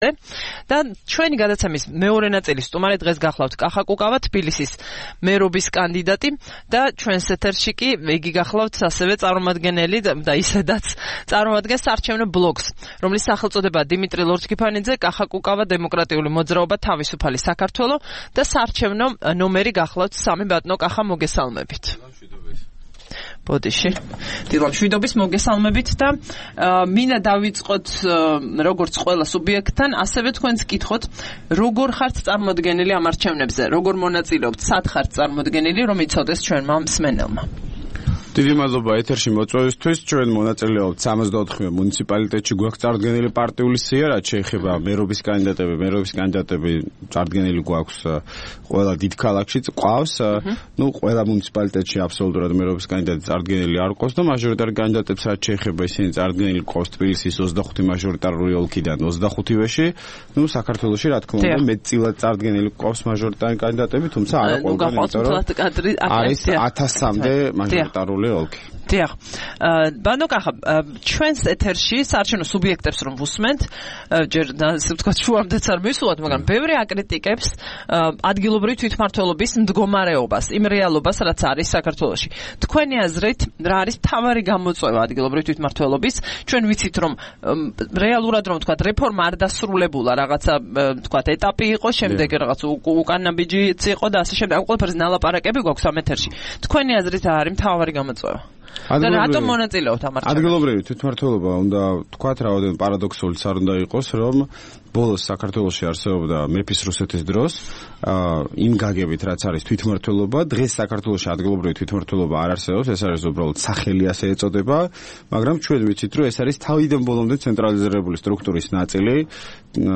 27 სექტემბერს რადიო თავისუფლების "დილის საუბრების" სტუმარი იყო კახა კუკავა, პარტია "თავისუფალი საქართველოს" ლიდერი.